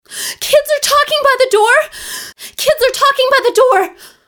surprised.wav